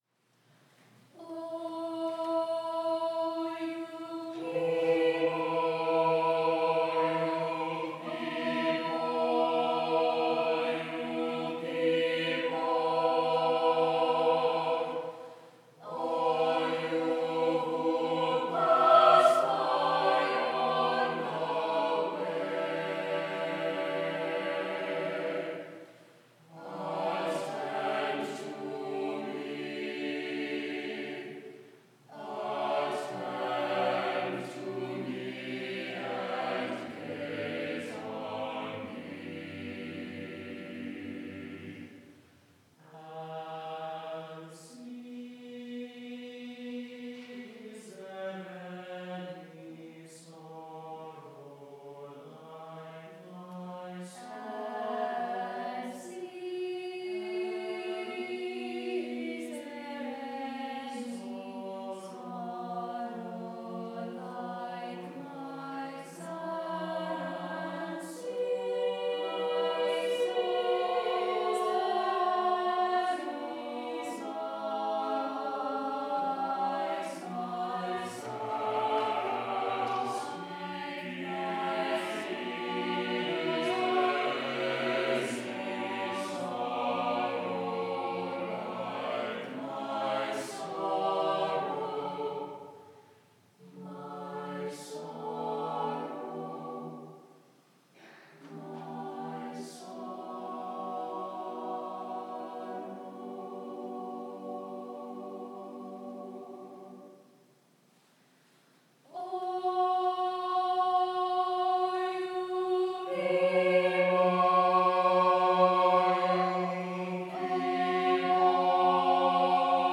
Choral Music of Jonathan Orwig – EVENSONG LLC
Premiere was March 25, 2025 by the Harvard Ferris Choral Fellows, during the morning prayer service.